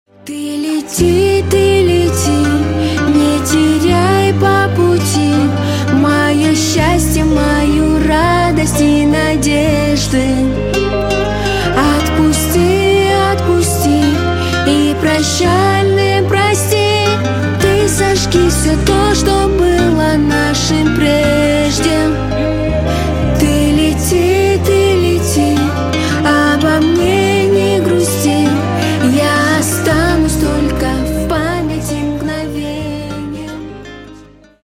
Спокойные И Тихие Рингтоны » # Грустные Рингтоны
Поп Рингтоны